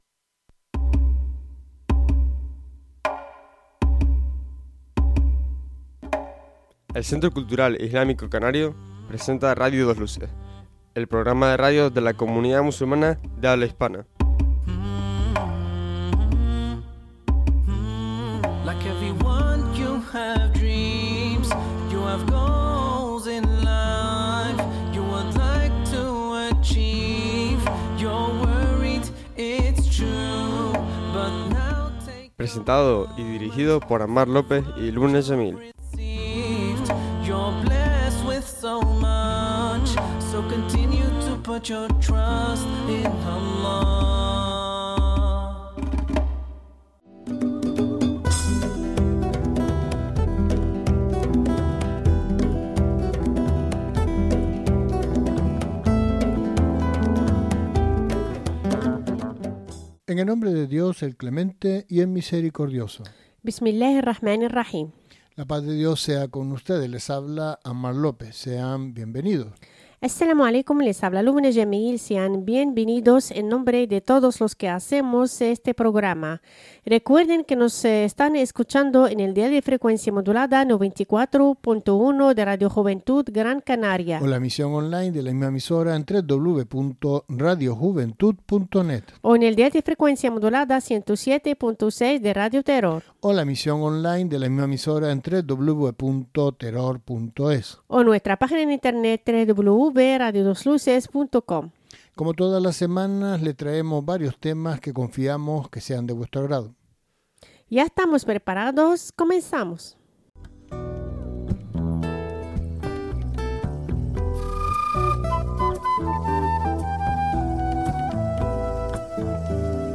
El programa completo en Radio 2 Luces. Con todas las secciones de actualidad, debate, entrevistas, música,...